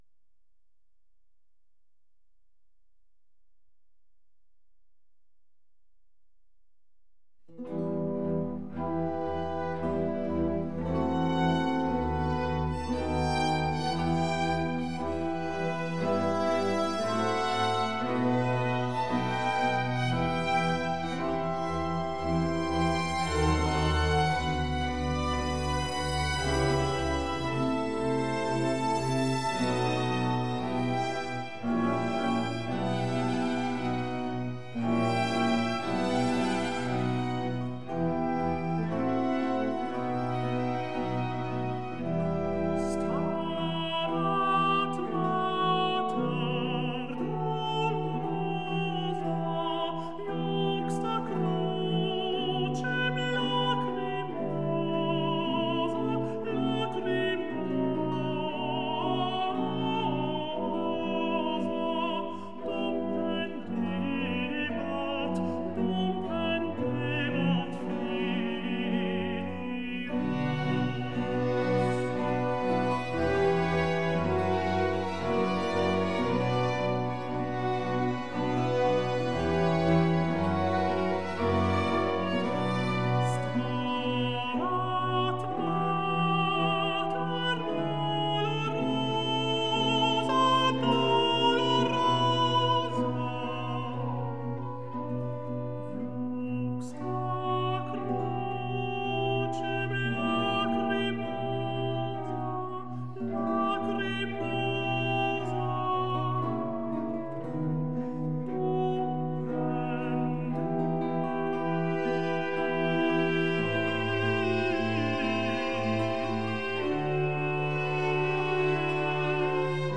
vocoded